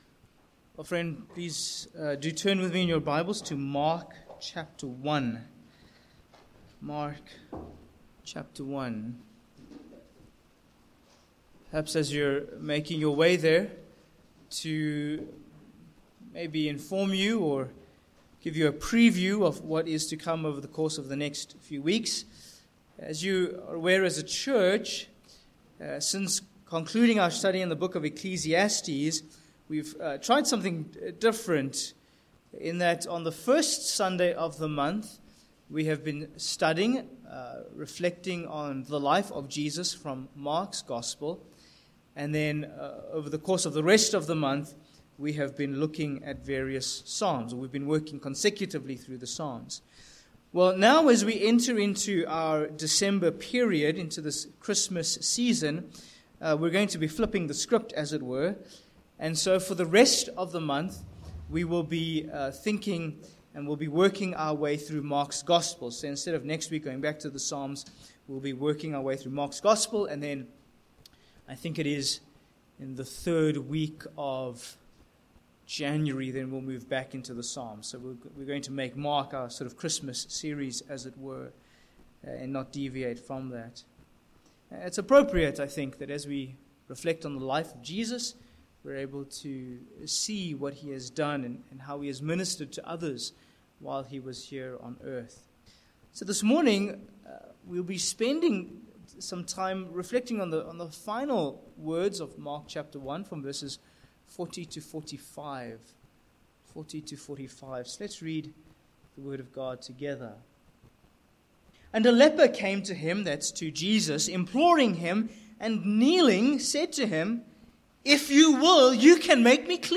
Sermon points: 1. The Leper’s Desperation v40